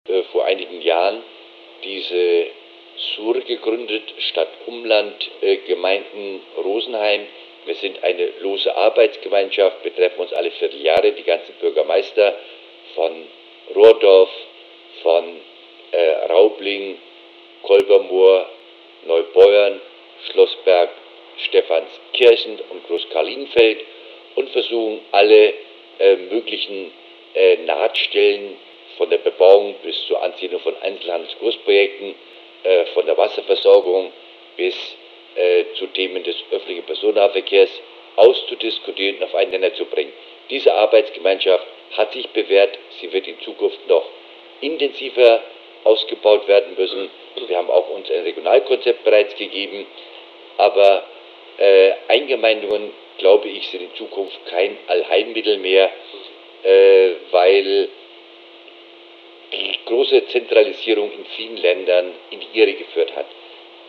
Zeitzeuge